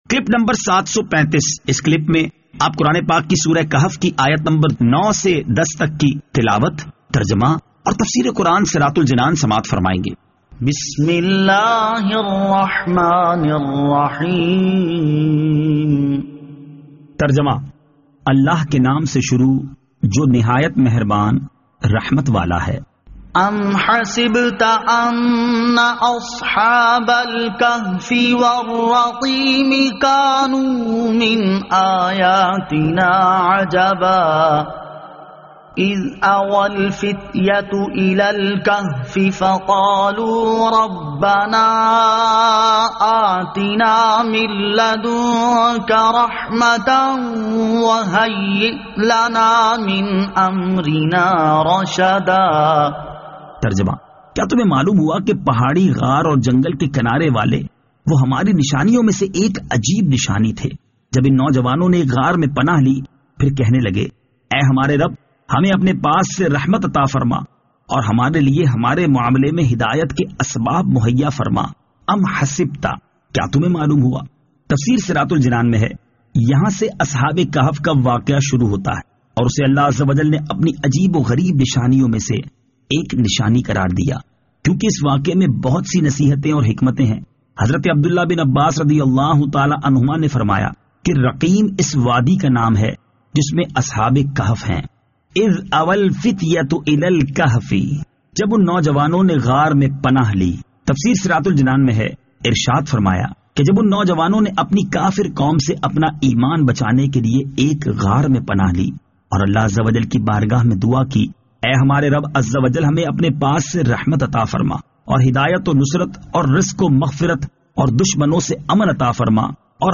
Surah Al-Kahf Ayat 09 To 10 Tilawat , Tarjama , Tafseer